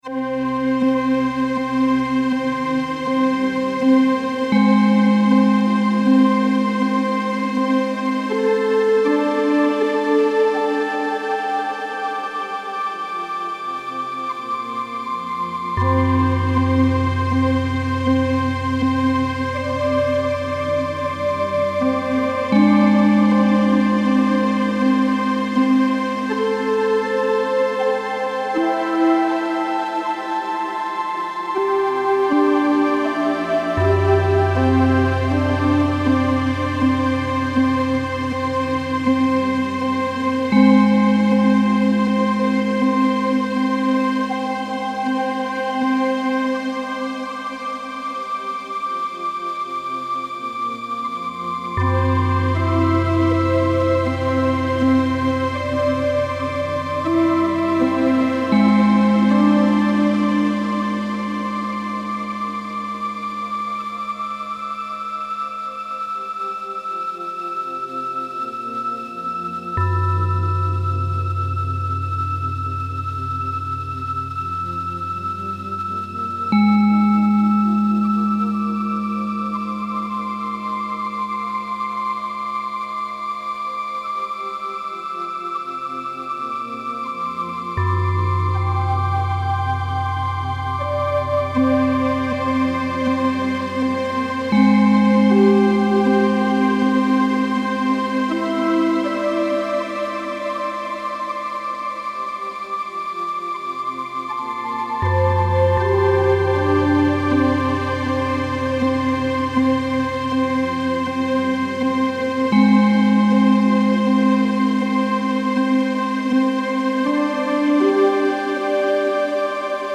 Using real measurements of cloud cover, sunlight, and daily solar cycles, the system transforms patterns in the sky into three-part musical compositions.